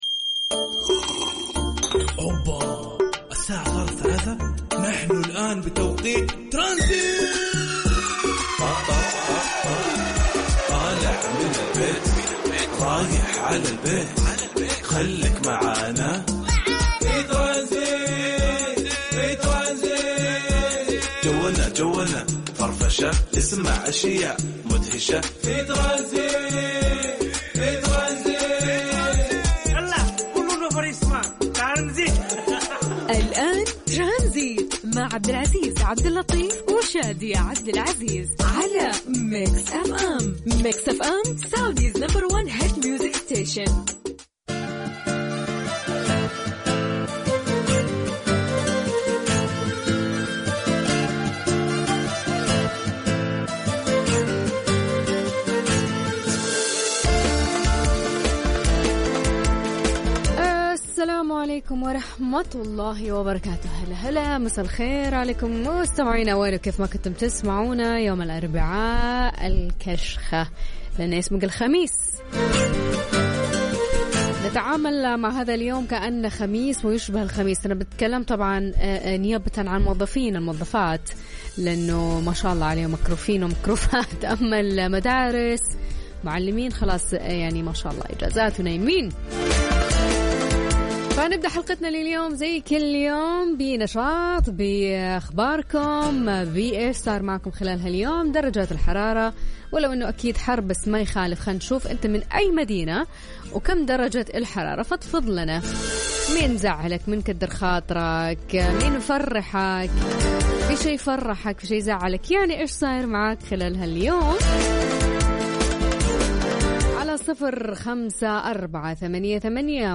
محطة ترفيهية تفاعلية يطرح مواضيع إجتماعية أو مواقف يناقشها مع المستمعين عن طريق وسائل التواصل وا تصا ت بهدف الترويح عن المستمعين بعد عناء يوم كامل حيث أن البرنامج يوافق توقيته مع المنصرفين من أعمالهم فقرات البرنامج: موضوع نقاش يتم طرحه من خ ل سكيتش مسجل يتم تسجيد في المواقف المختلفة التي نواجهها في حياتنا اليومية مع أخذ إستط عات الرأي من أماكن تجمعات مختلفة في مدينة الرياض